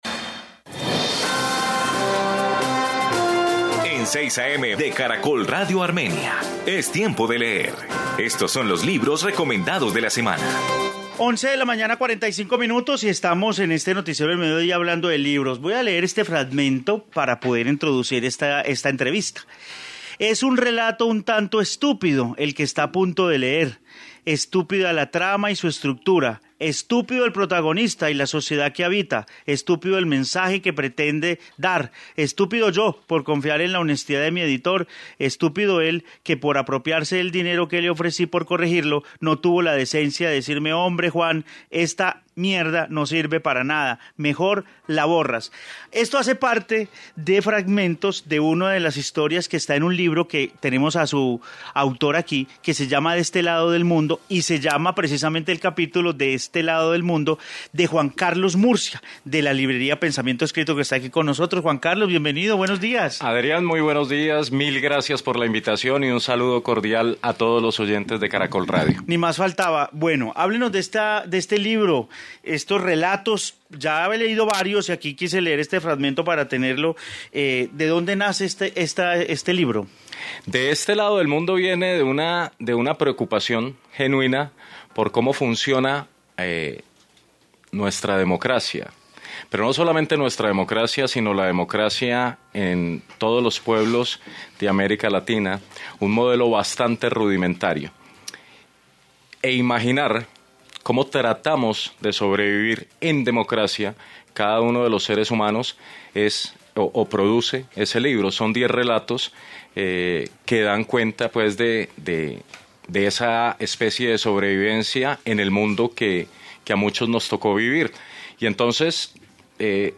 Informe libro y nueva editorial